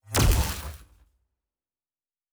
pgs/Assets/Audio/Sci-Fi Sounds/Weapons/Weapon 13 Shoot 1.wav at master
Weapon 13 Shoot 1.wav